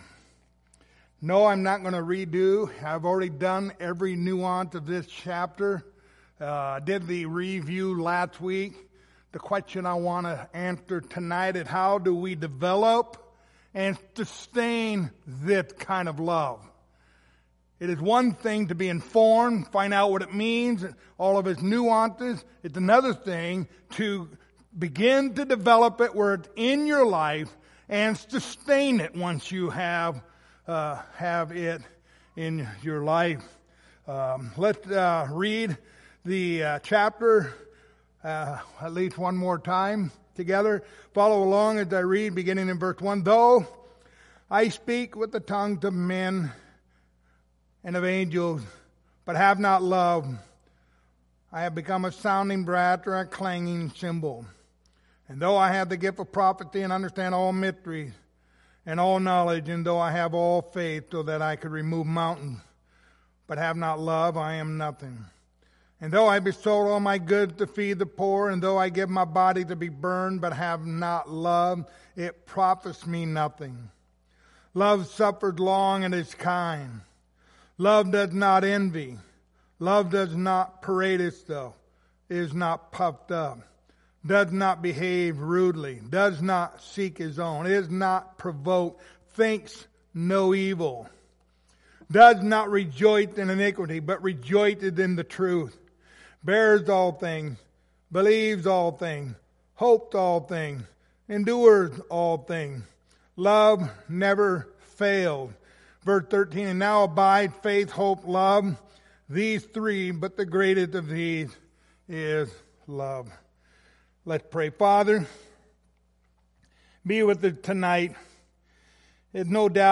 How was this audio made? Passage: 1 Corinthians 13 Service Type: Wednesday Evening